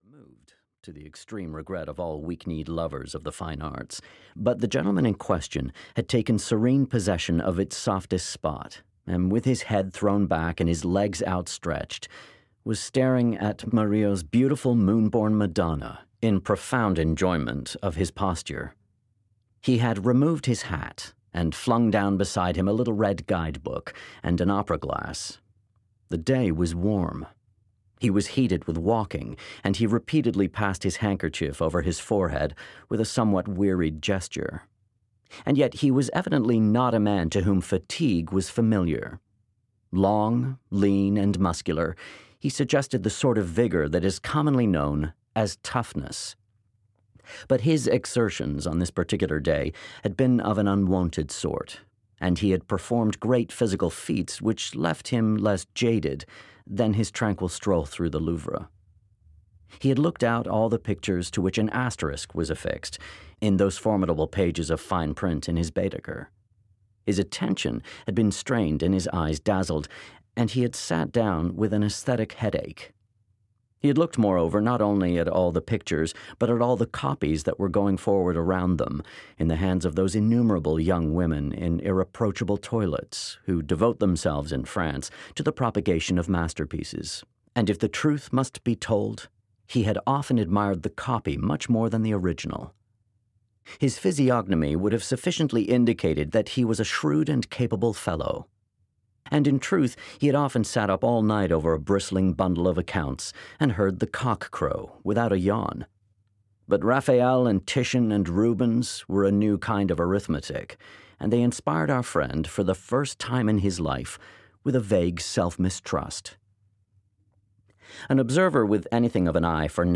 The American (EN) audiokniha
Ukázka z knihy